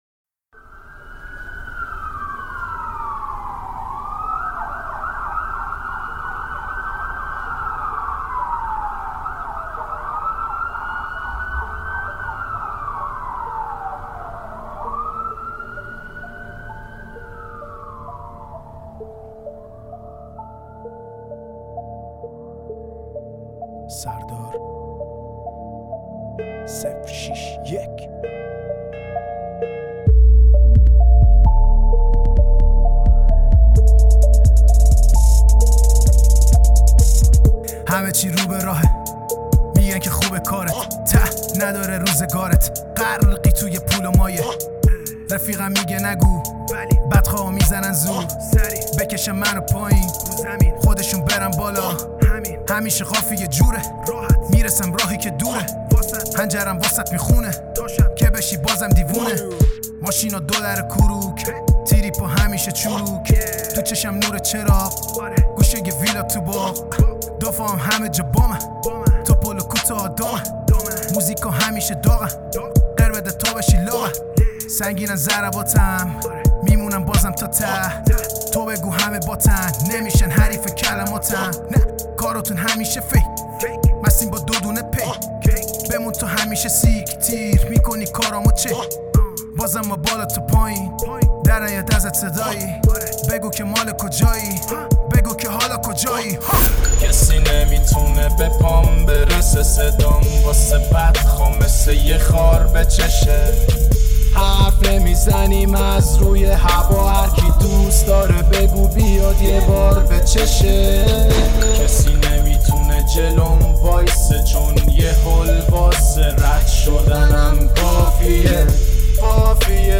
Hiphop
Trap